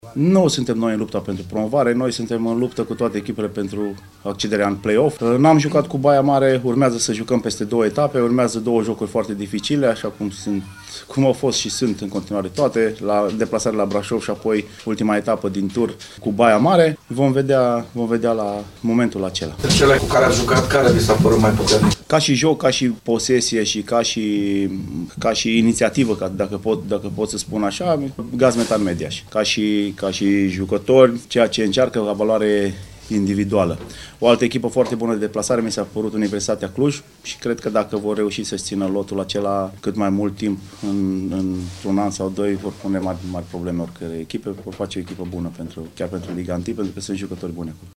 Apoi ambii antrenori şi-au mai expus punctele de vedere despre B2-ul fotbalistic de la noi: